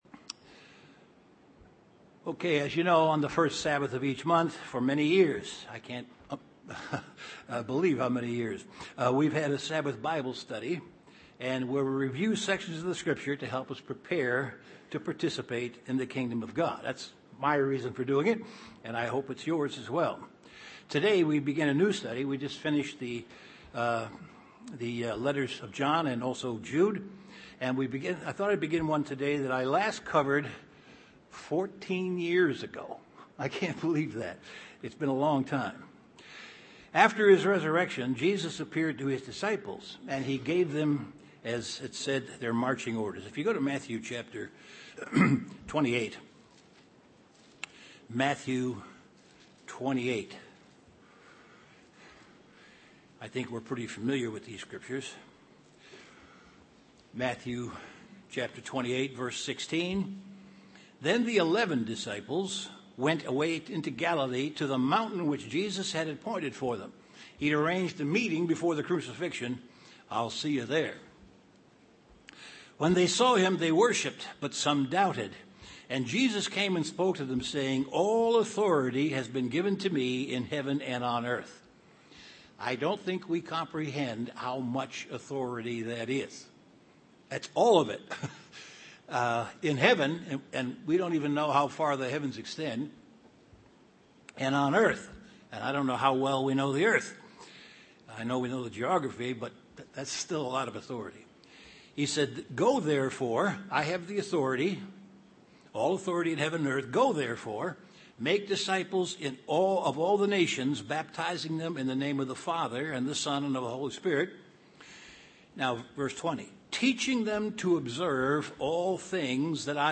The first session of an in-depth Bible Study on the Sermon on the Mount. This study discusses the Beatitudes.